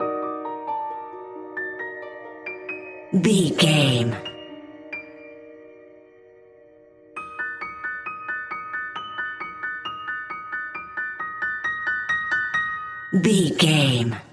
Aeolian/Minor
E♭
eerie
ominous